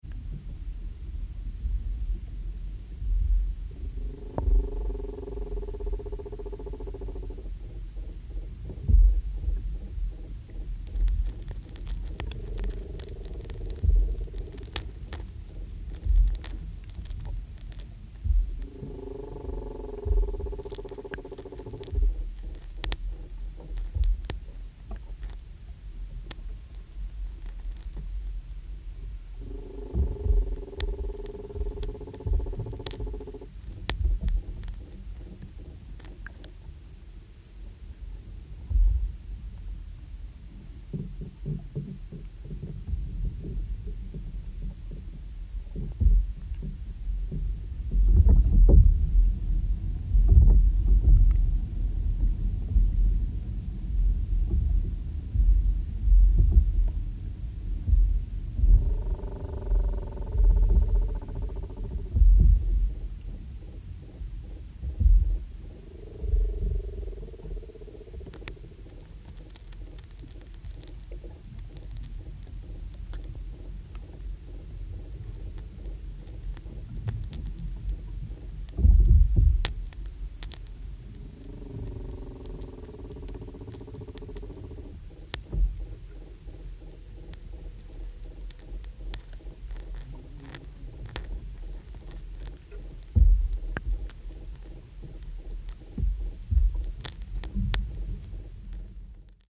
We have been making a series of field recordings of trees.
More recently we have been using contact mics on the trunks of the trees to hear the sound of the rising sap in the xylem tubes. The recordings reveal surprising sloshing and guzzling sounds, as well as knocks, creaks and groans within the timber.
Recordings using two contact mics on the trunk
Large Sycamore in the woods